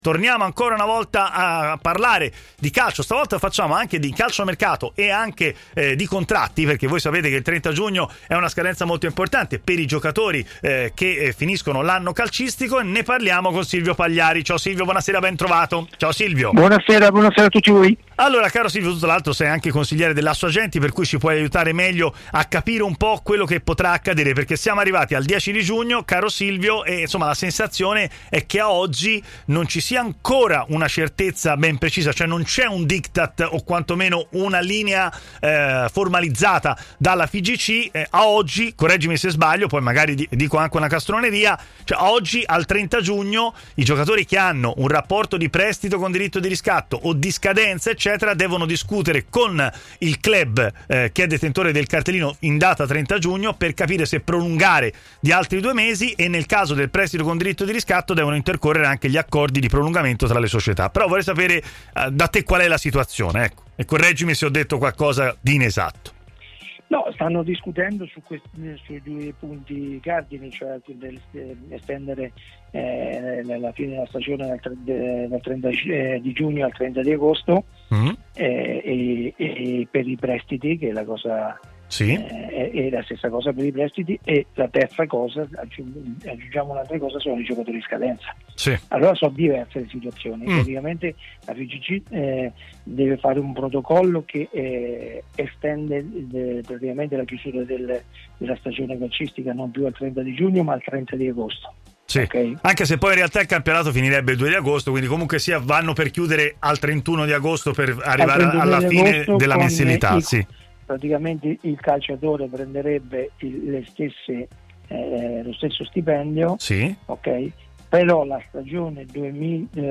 ha parlato collegandosi in diretta con Stadio Aperto, trasmissione di TMW.